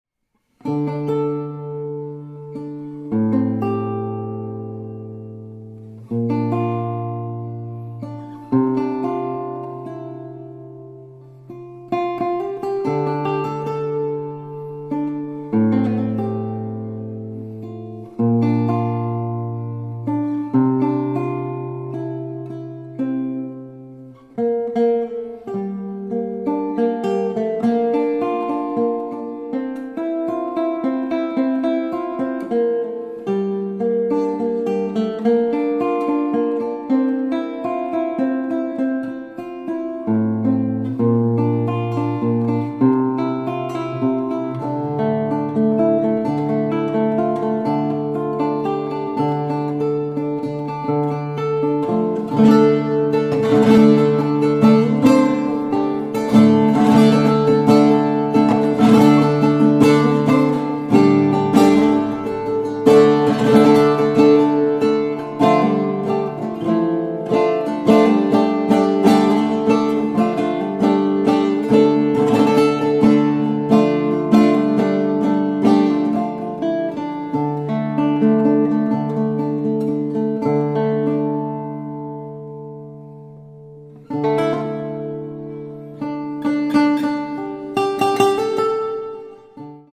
composer, lute & oud player from Japan
Contemporary
, Lute , Relaxing / Meditative